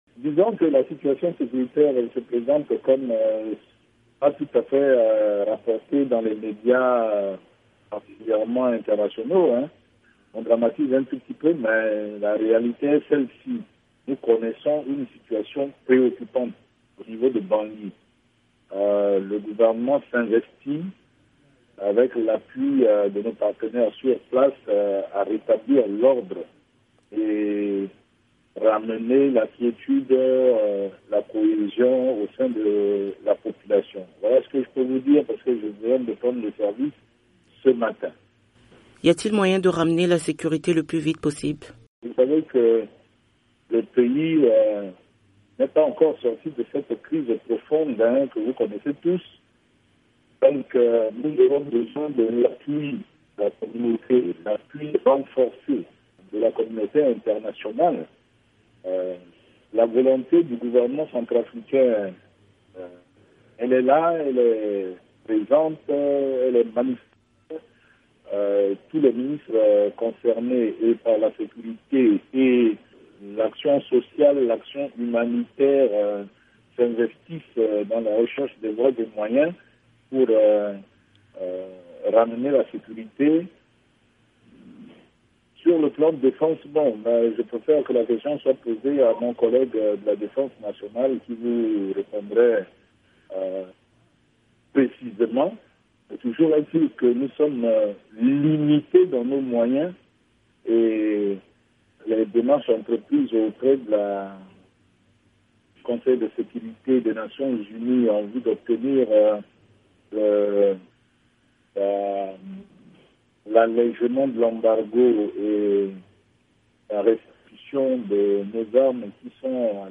Dans une interview à VOA Afrique, le nouveau ministre de la Sécurité Pierre Chrysostome Sambia explique que le gouvernement centrafricain attend que le Conseil de sécurité allège l’embargo sur les armes en RCA, mais aussi la restitution des armes de l’armée centrafricaine qui sont présentement à la disposition de forces onusiennes.